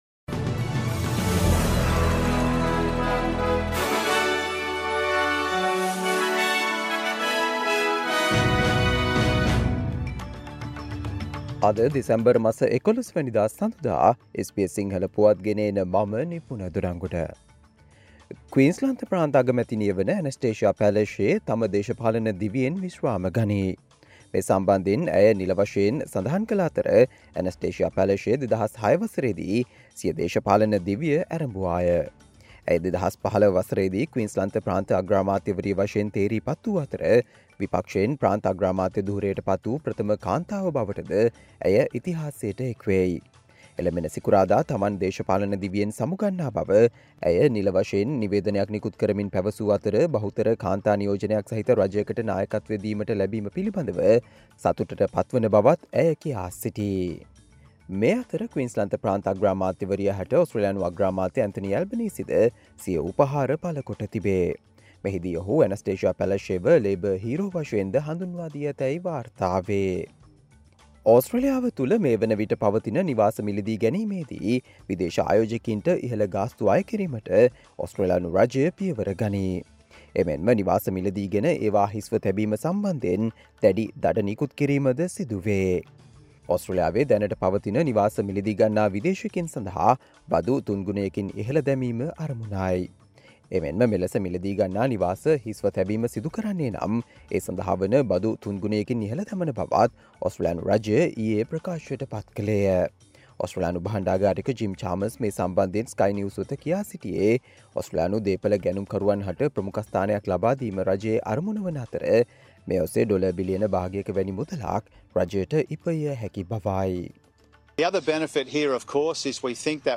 Australia news in Sinhala, foreign and sports news in brief - listen, Monday 11 December 2023 SBS Sinhala Radio News Flash